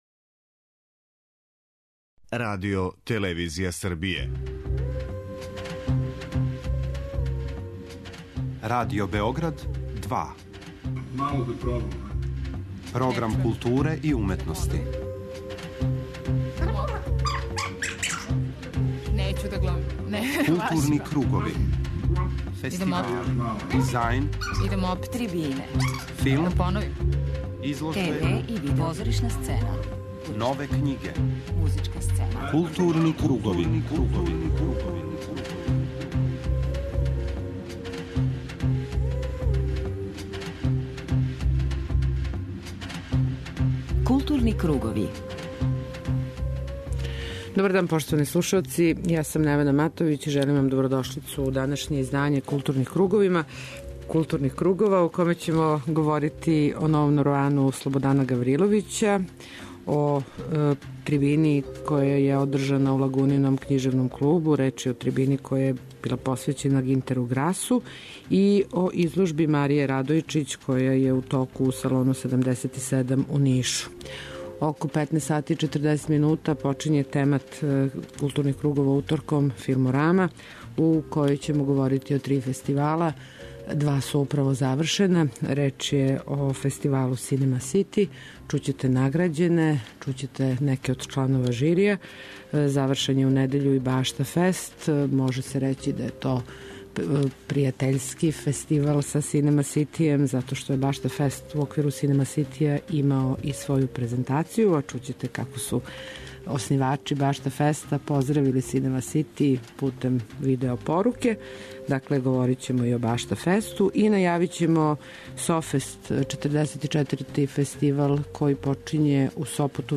Чућете изјаве чланова жирија и награђене ауторе